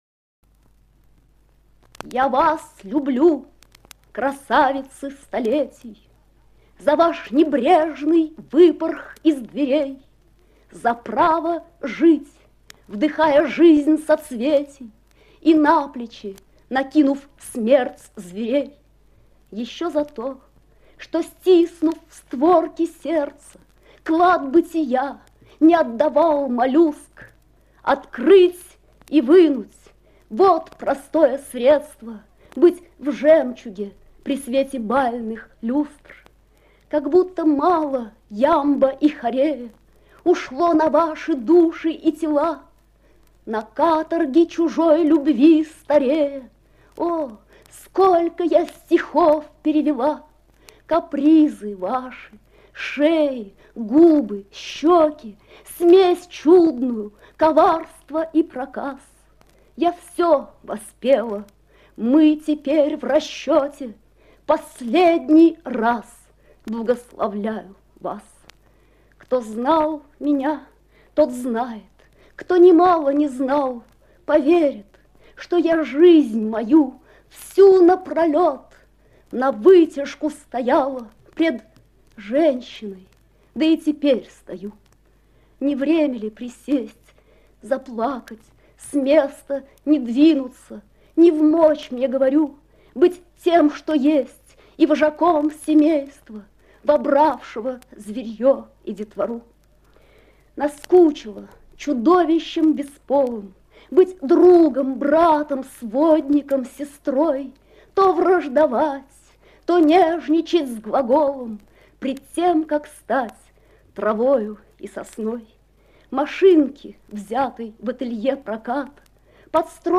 2. «Белла Ахмадулина (читает автор) – Я вас люблю, красавицы столетий» /
bella-ahmadulina-chitaet-avtor-ya-vas-lyublyu-krasavitsy-stoletij